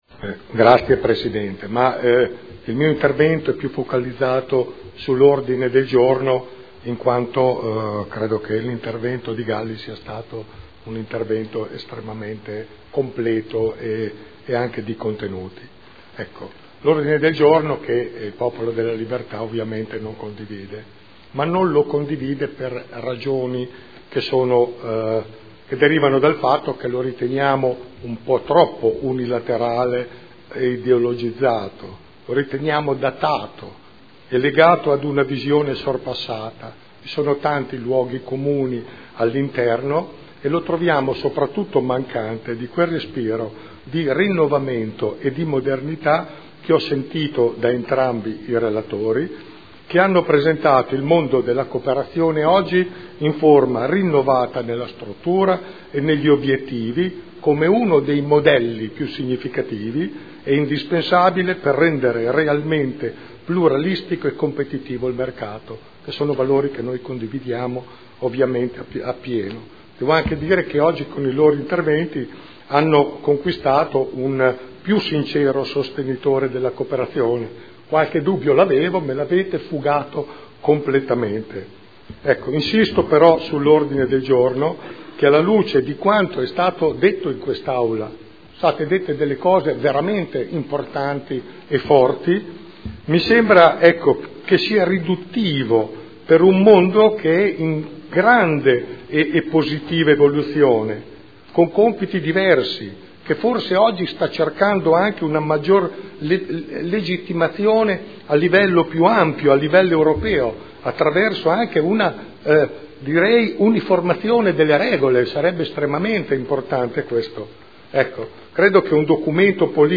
Seduta del 22/11/2012. Dibattito su celebrazione dell’Anno internazionale delle cooperative indetto dall’ONU per il 2012